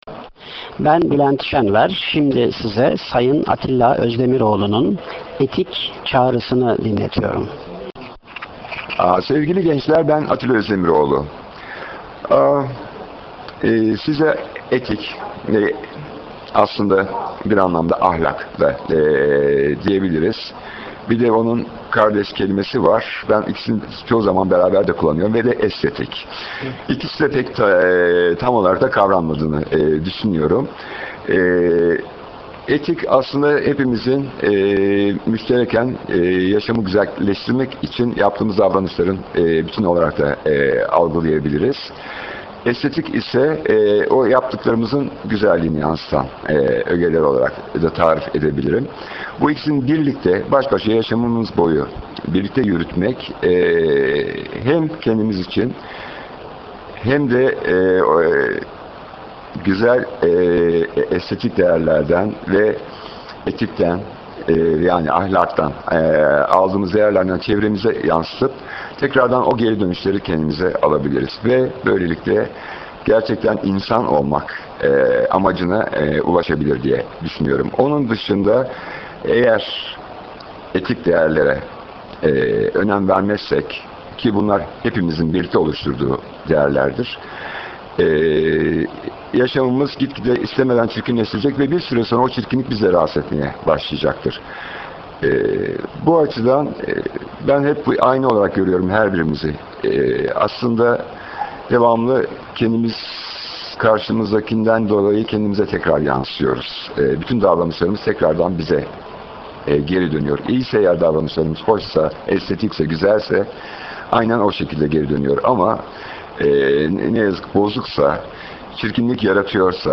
Kendi Sesiyle